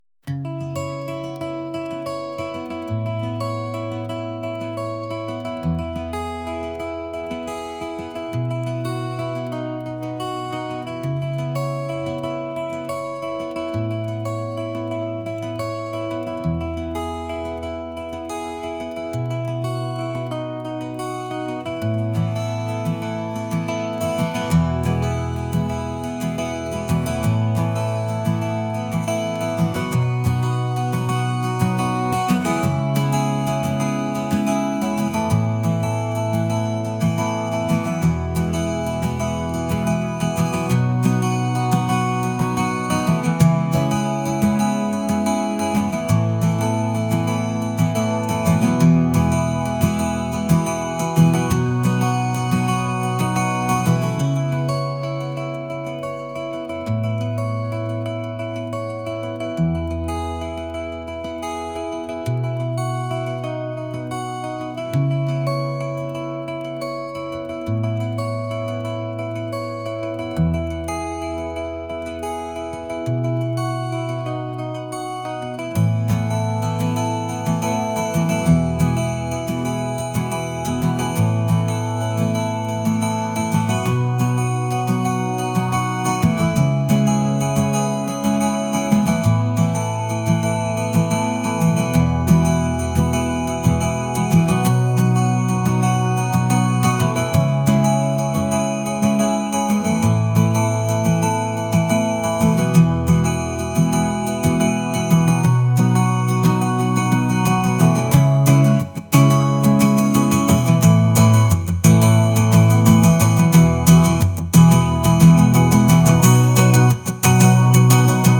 indie | acoustic | pop